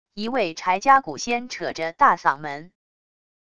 一位柴家蛊仙扯着大嗓门wav音频